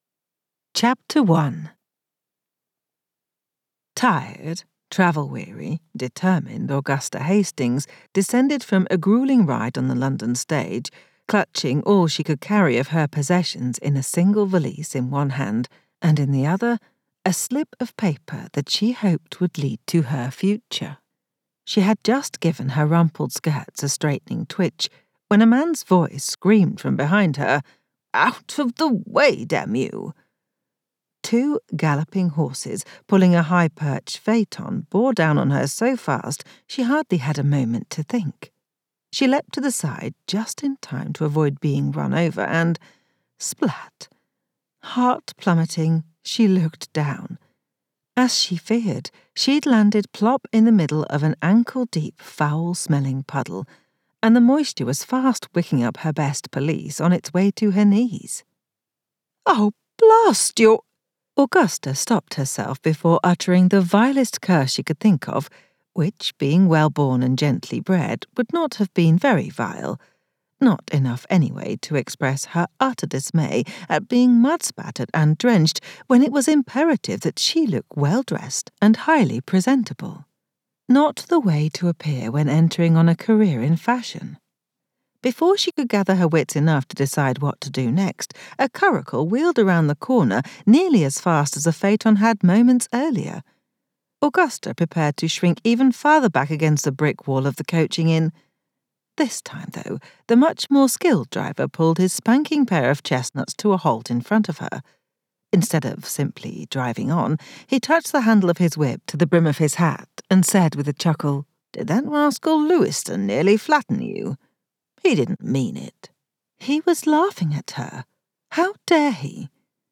Listen to a sample of the audiobook!